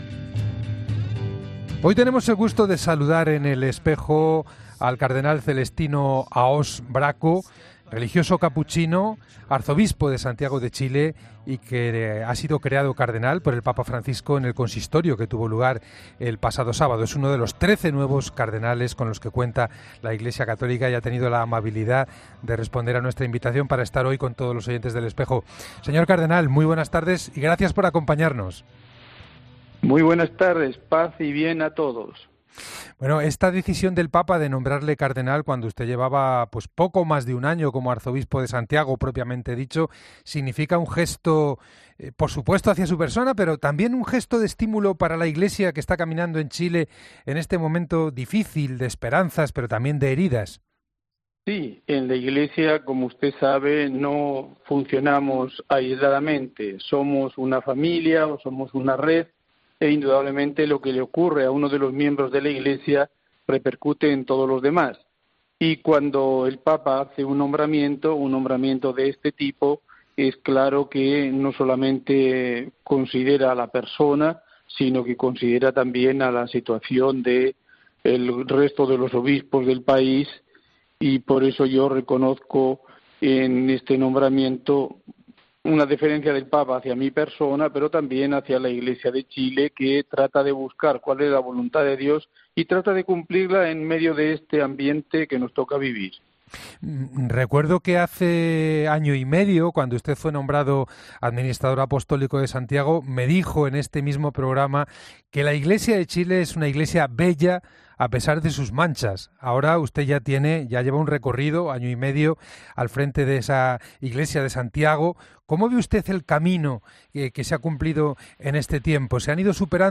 El religioso ha hablado sobre esta decisión, sobre su nueva labor y sobre la situación que vive Chile en el programa de El Espejo de la Cadena COPE: